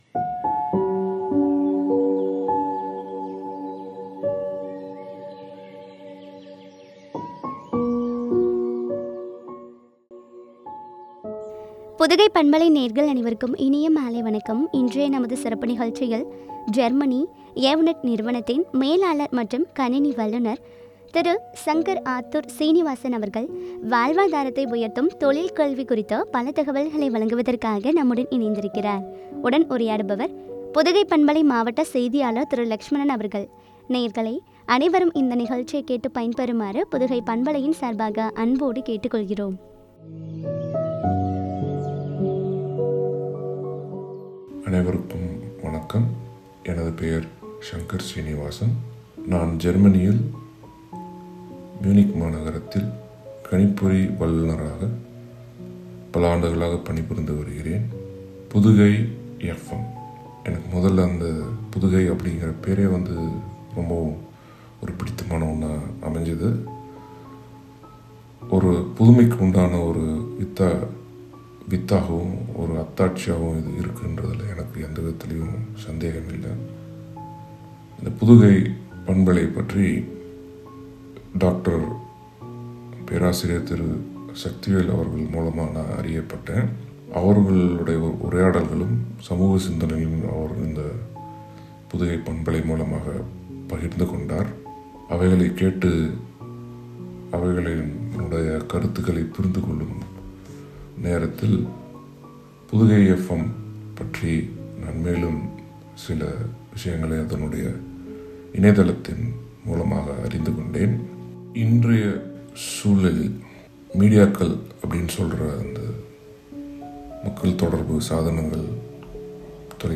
வாழ்வாதாரத்தை உயர்த்தும் தொழிற்கல்வி பற்றிய உரையாடல்.